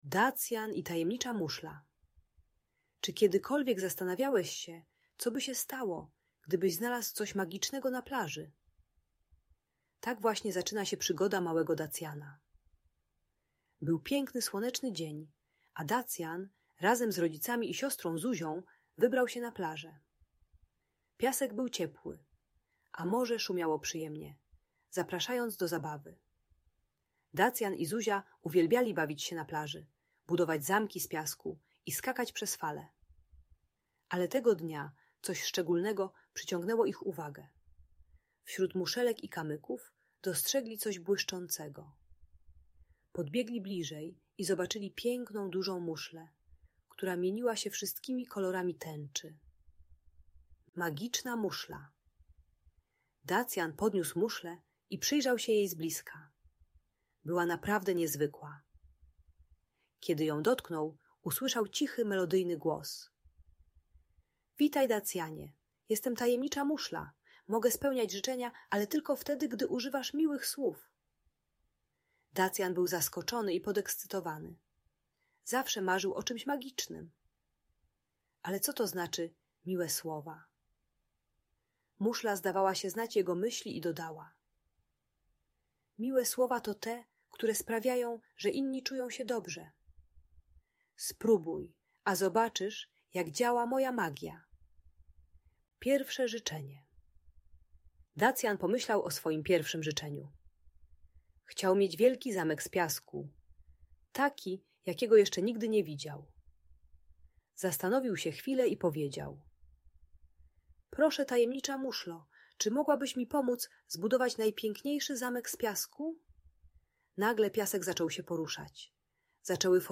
Tajemnicza Muszla - Niepokojące zachowania | Audiobajka